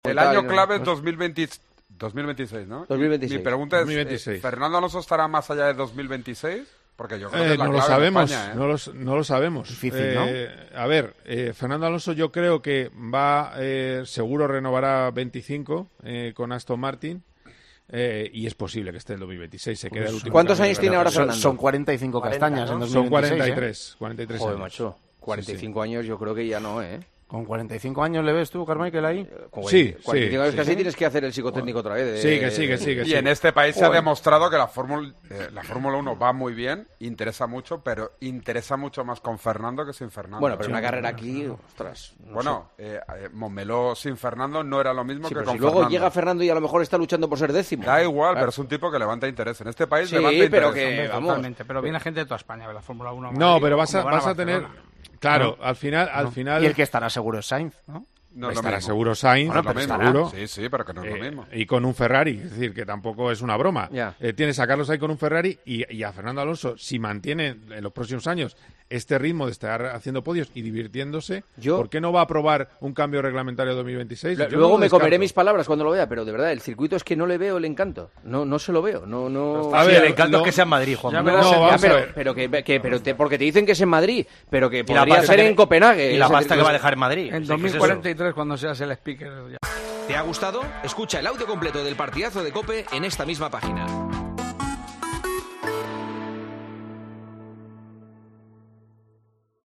Pincha en el audio que aparece a continuación para conocer la opinión de los tertulianos y comentaristas.